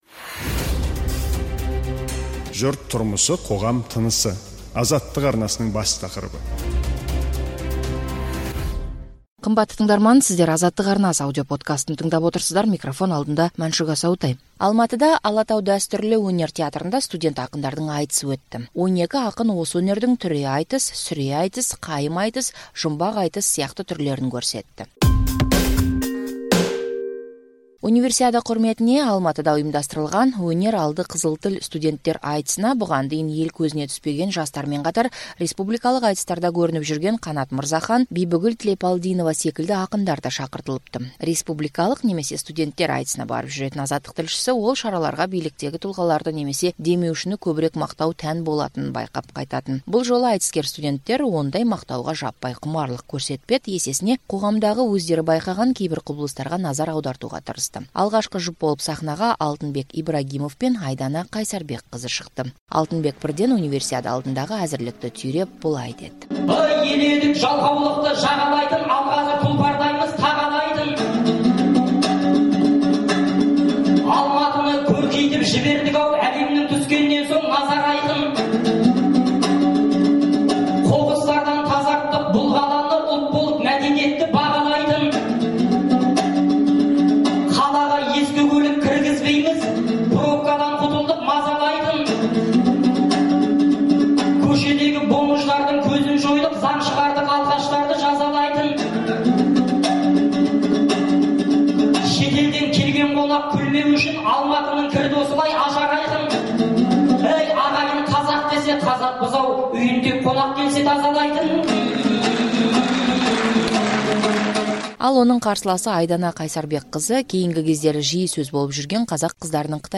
Студент ақындар айтысы
Алматыда «Алатау» дәстүрлі өнер театрында студент ақындардың айтысы өтті. 12 ақын осы өнердің бірнеше түрін көрсетті.